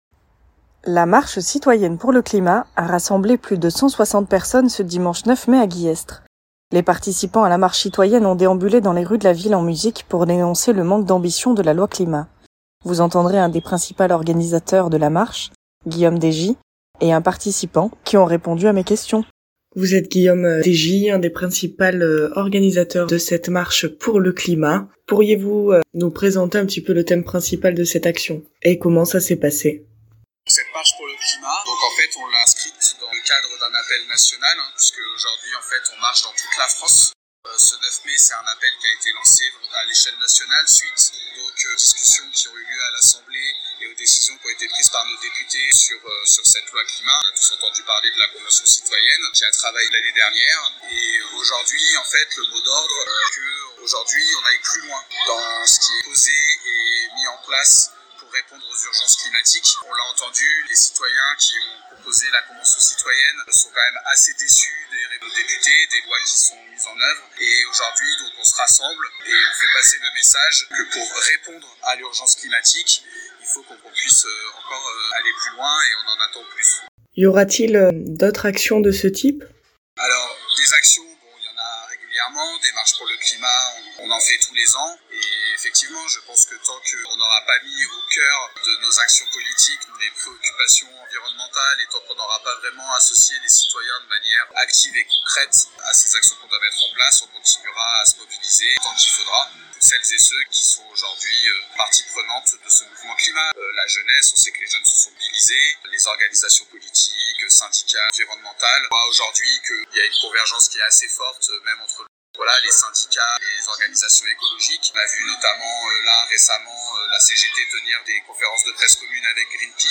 un participant